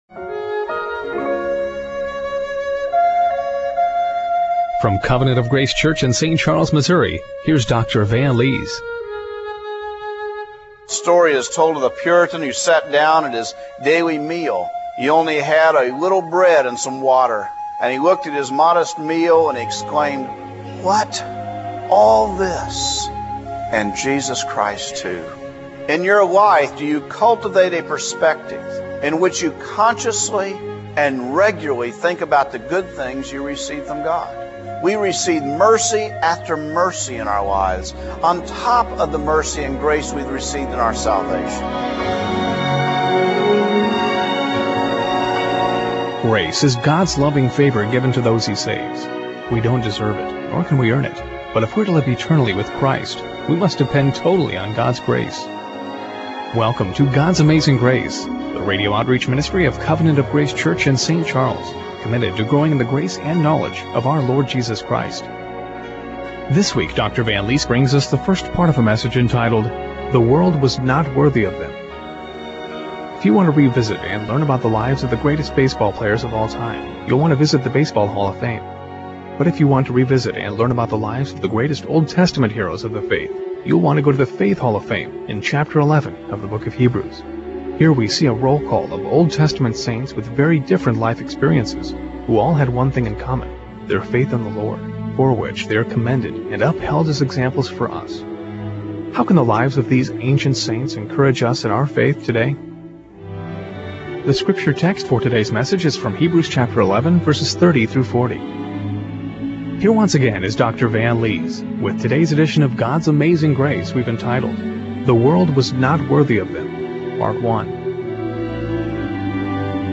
Hebrews 11:30-40 Service Type: Radio Broadcast How can the lives of the Old Testament saints encourage us in our faith today?